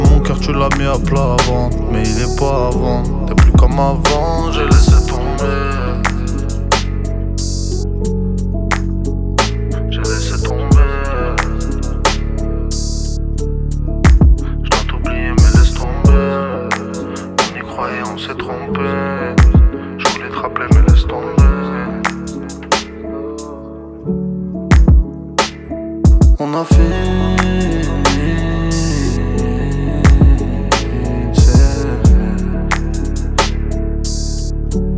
Rap Hip-Hop Rap
Жанр: Хип-Хоп / Рэп